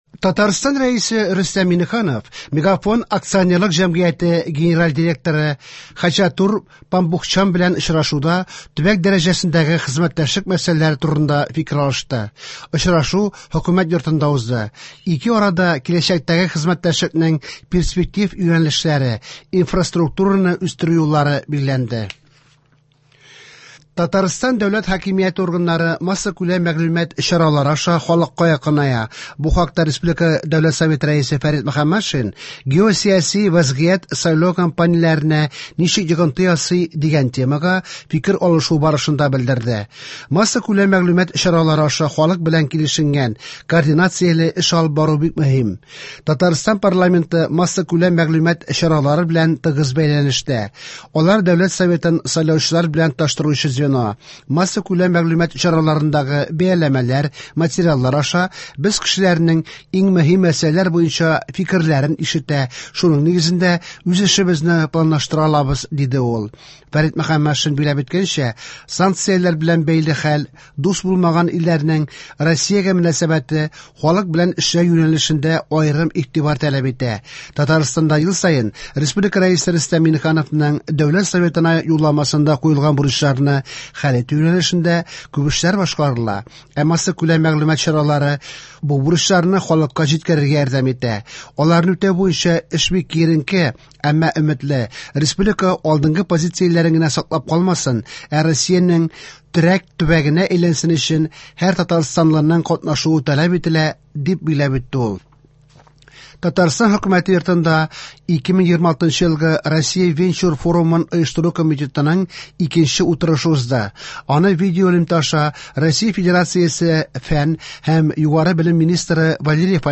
Иртәнге чыгарылыш